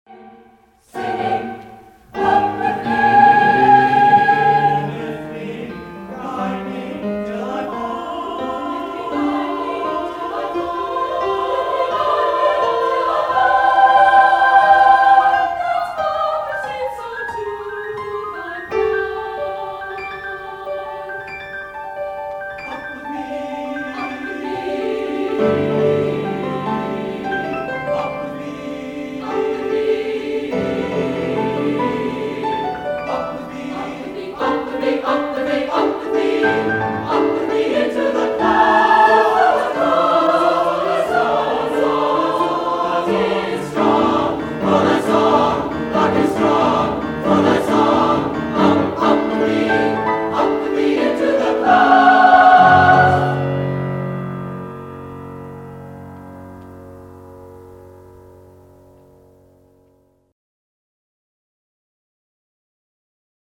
Vocal/Choral
for SATB chorus and piano